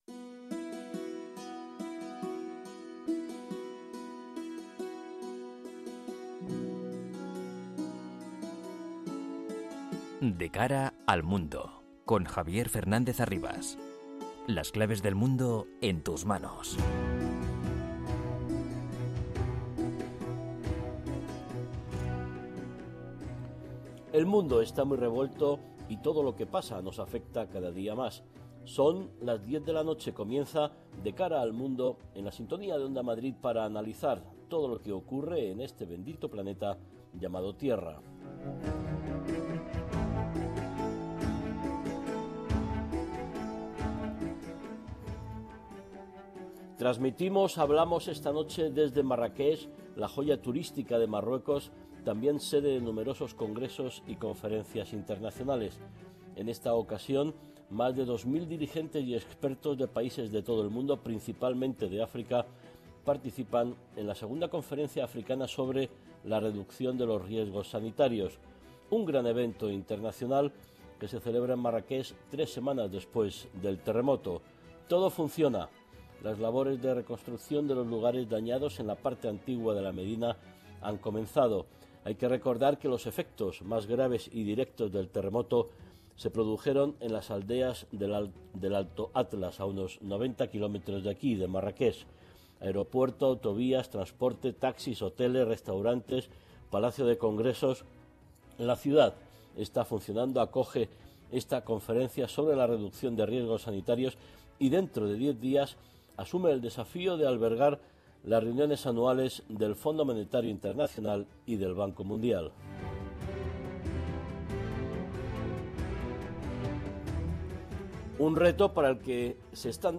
con entrevistas a expertos y un panel completo de analistas.